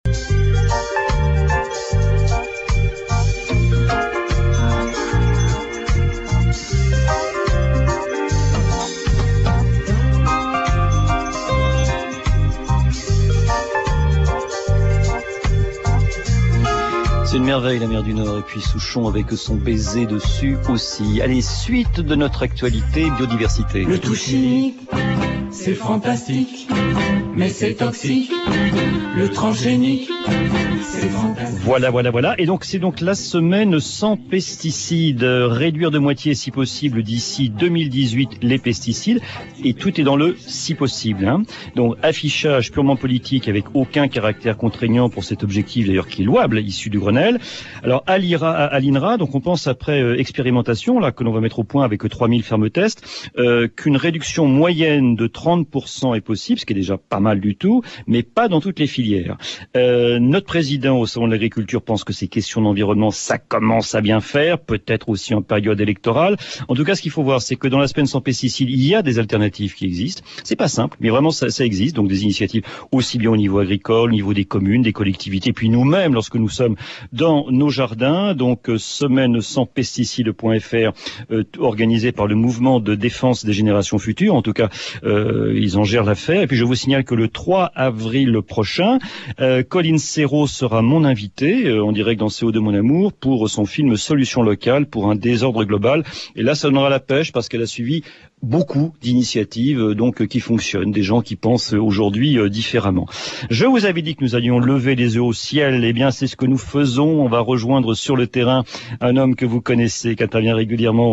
(extrait chanson des Homotoxicus)